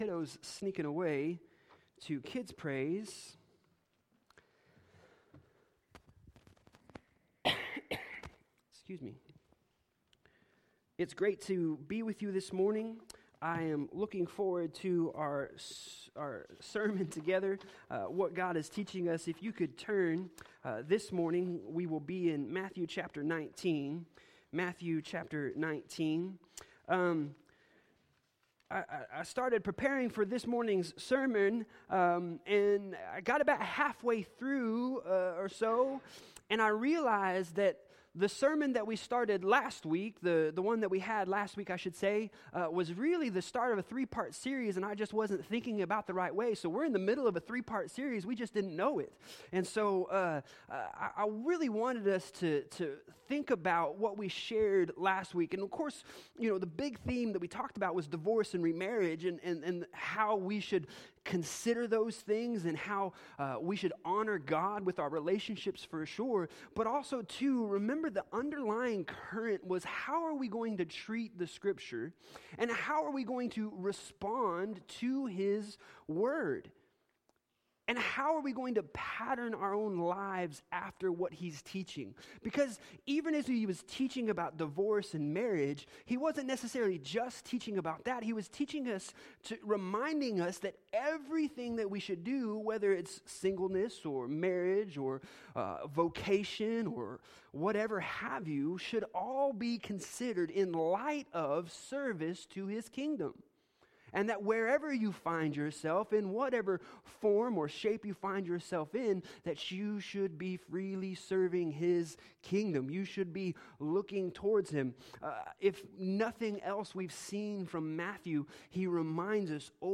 Passage: Matthew 19:13-15 Service Type: Sunday Morning